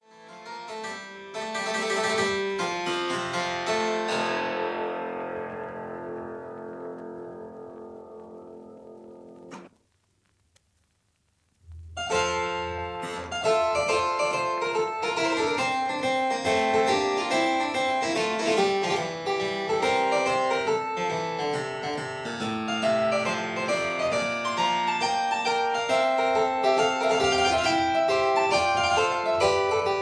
two manual harpsichord